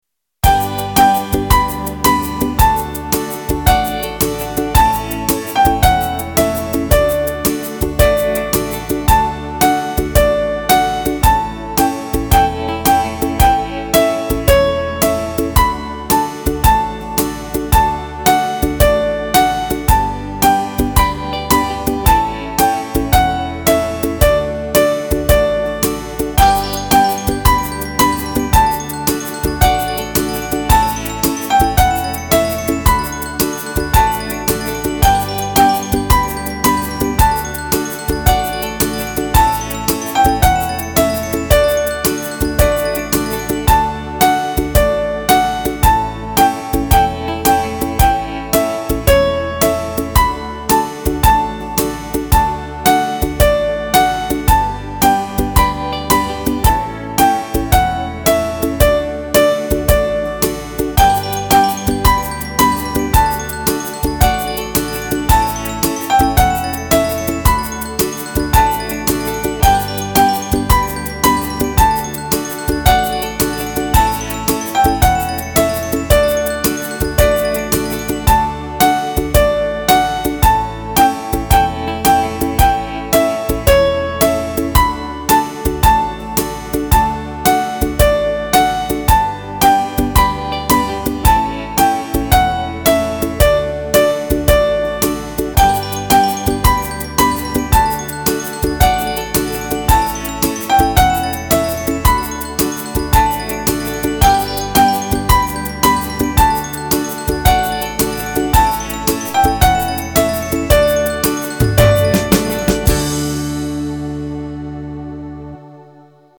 Музыкальная игра для малышей.